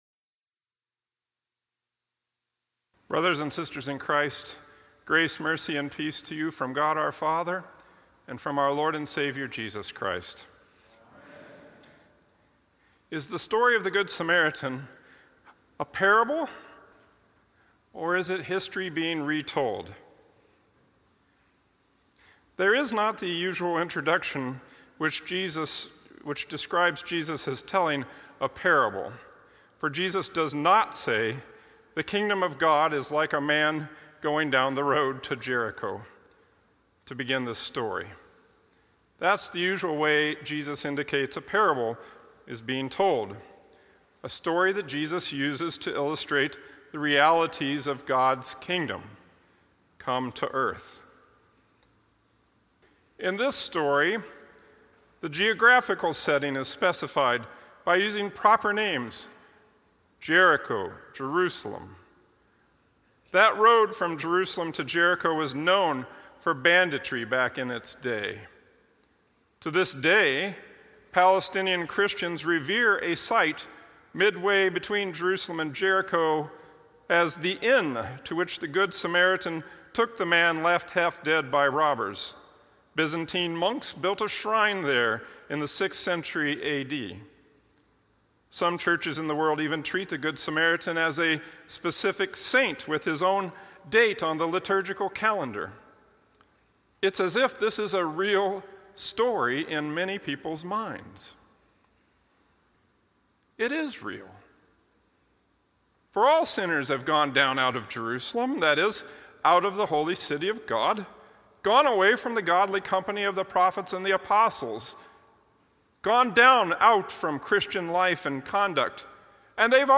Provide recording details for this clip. Thirteenth Sunday after Trinity